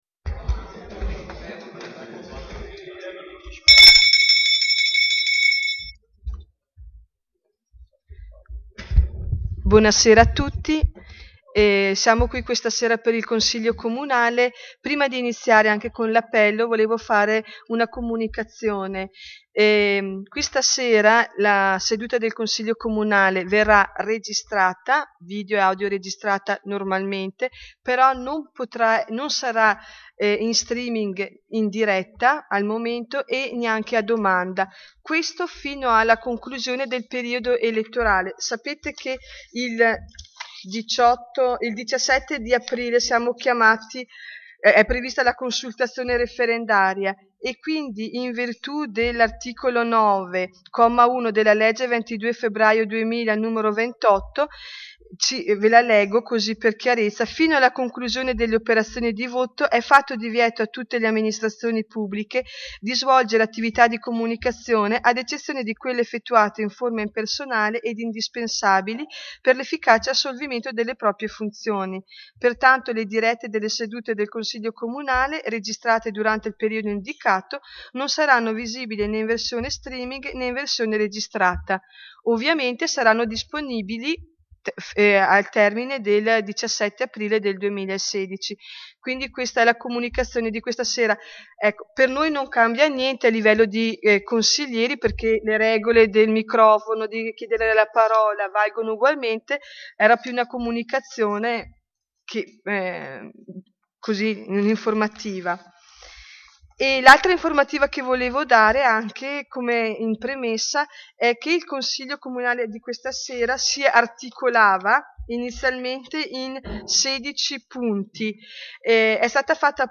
Audio della seduta